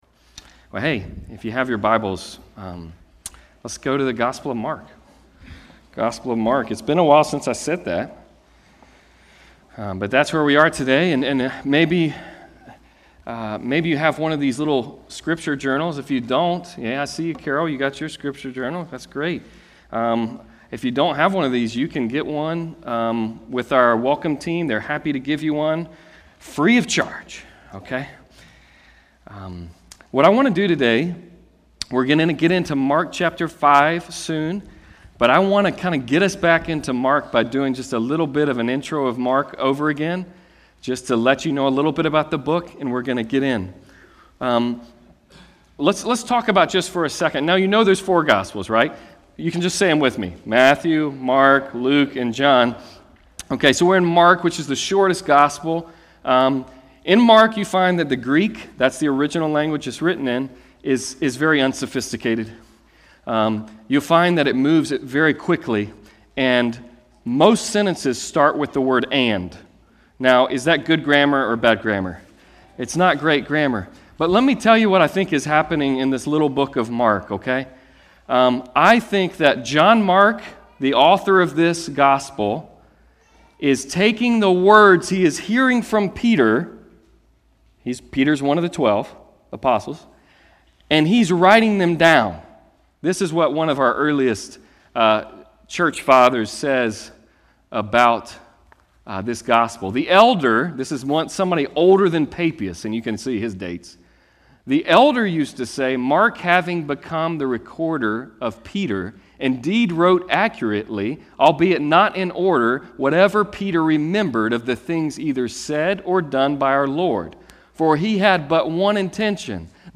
Passage: Mark 5:1-20 Service Type: Sunday Service